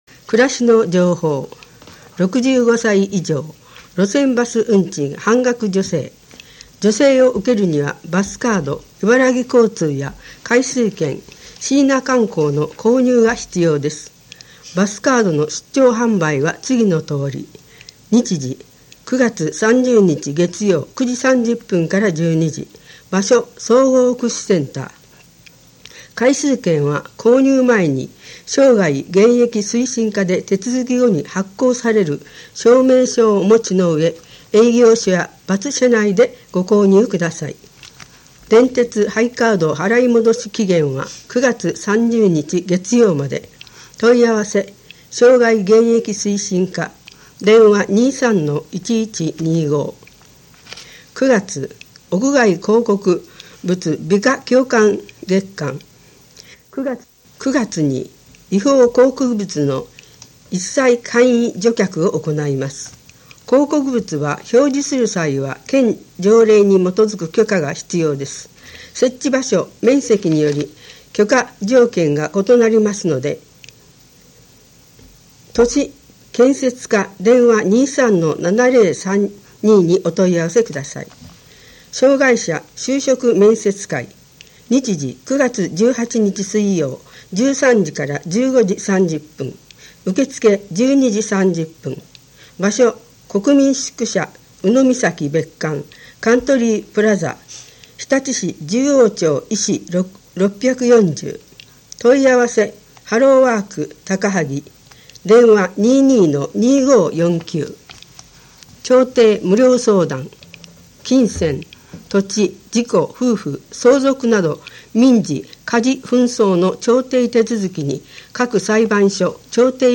声の広報